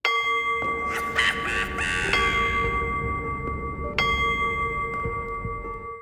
cuckoo-clock-03.wav